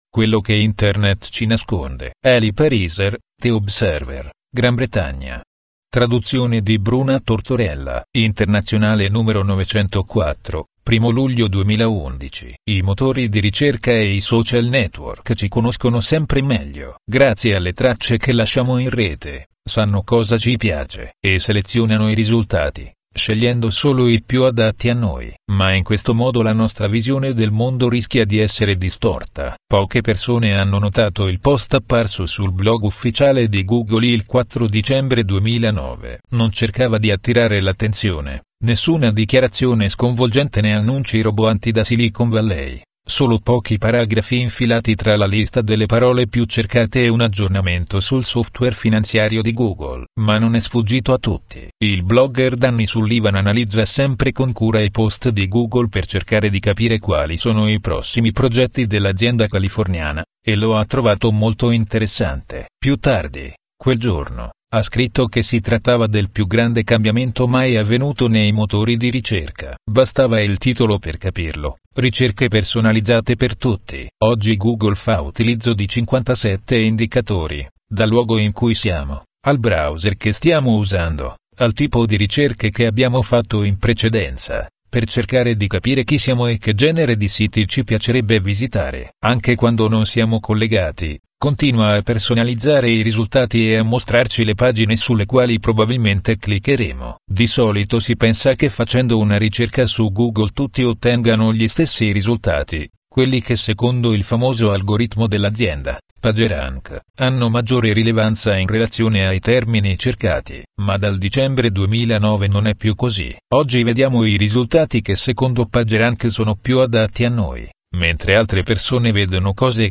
Ho ascoltato la lettura di un articolo abbastanza lungo apparso il 6 luglio 2011 sulla rivista "Internazionale": vorrei consigliare a tutti di leggerlo o di ascoltarlo con attenzione.